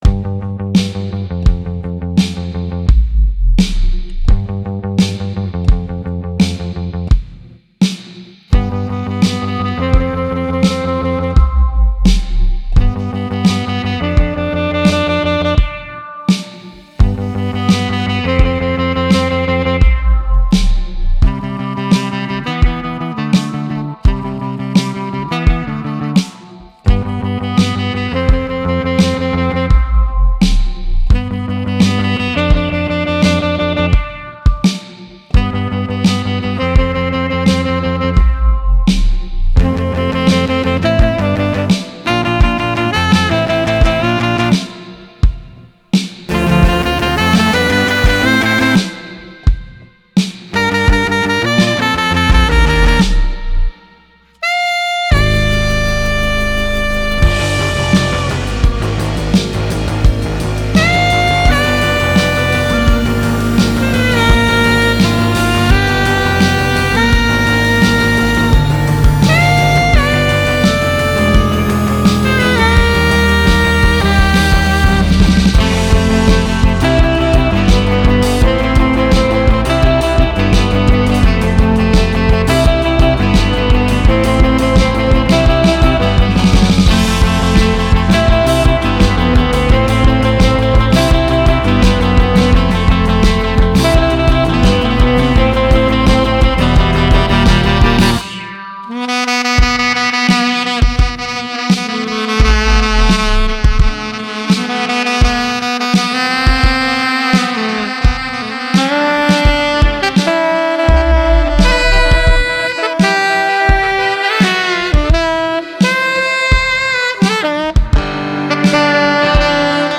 A l'occasion de la venue à Paris du saxophoniste iconique, Donny Mc Caslin, retour sur notre rencontre dans le cadre du dernier festival Jazz sous les pommiers à Coutances